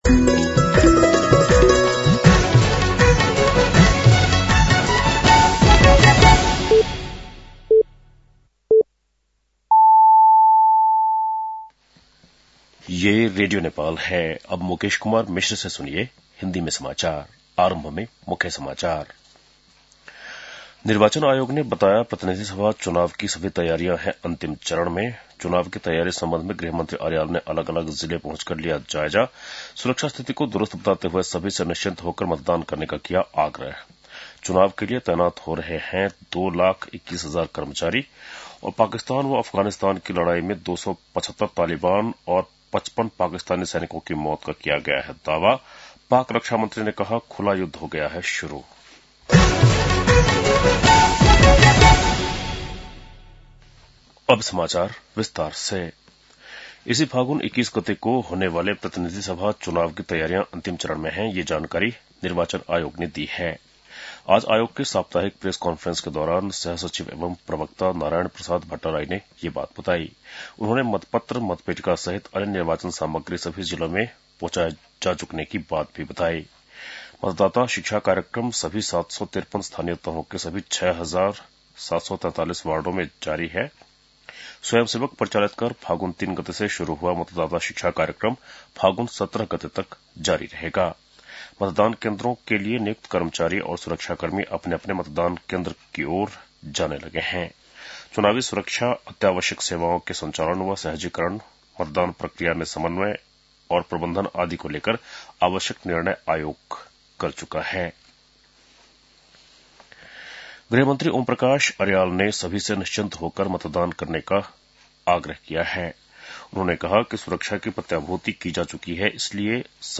बेलुकी १० बजेको हिन्दी समाचार : १५ फागुन , २०८२